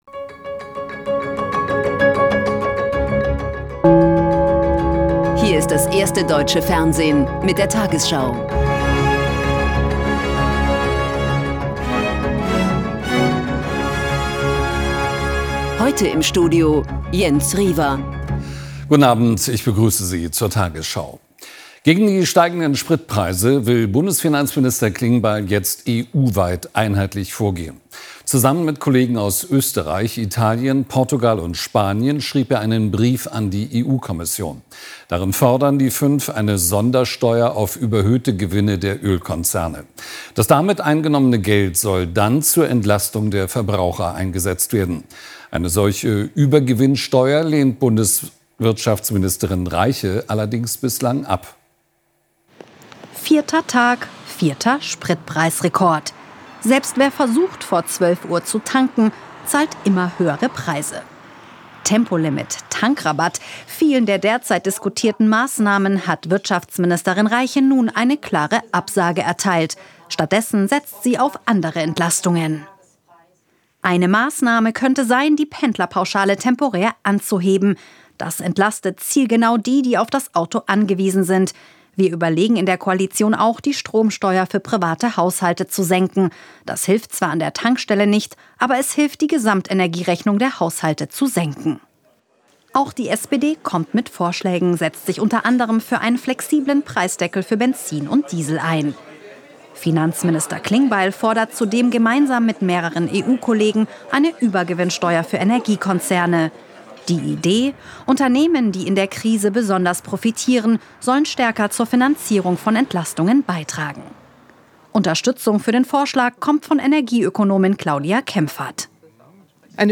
tagesschau 20:00 Uhr, 04.04.2026 ~ tagesschau: Die 20 Uhr Nachrichten (Audio) Podcast